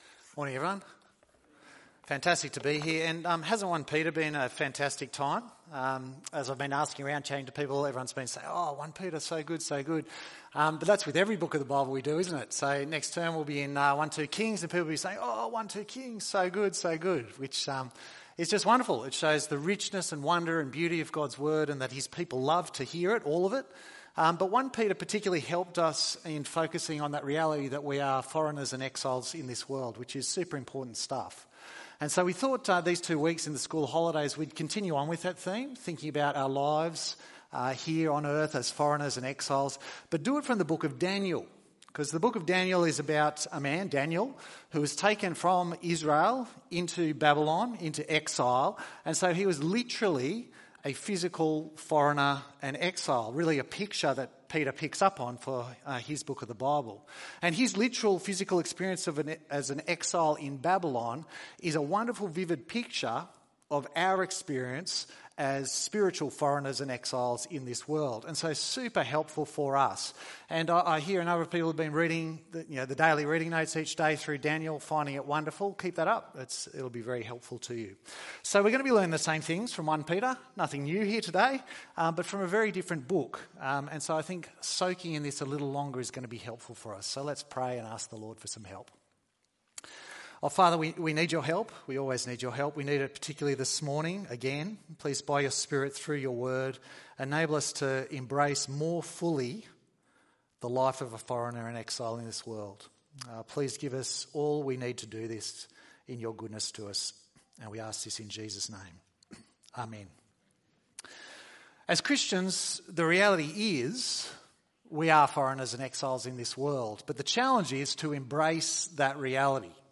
Daniel - a foreigner and exile ~ EV Church Sermons Podcast